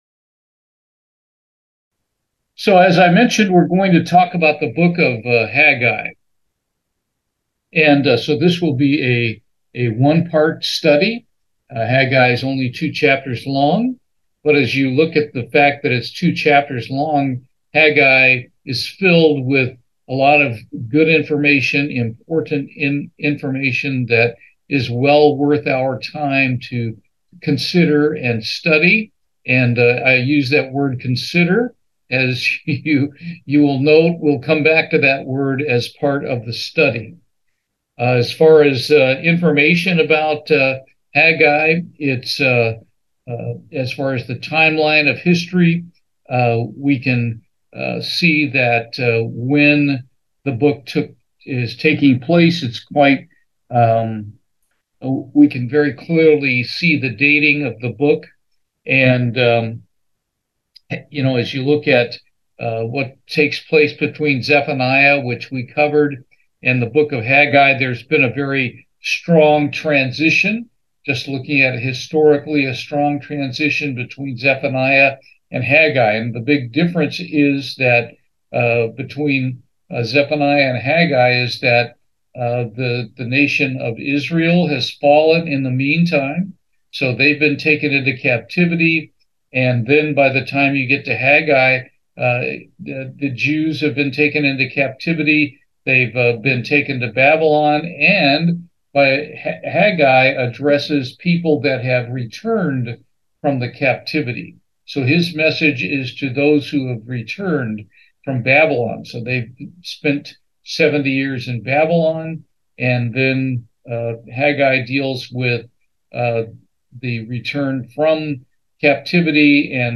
Bible Study, Haggai
Given in Houston, TX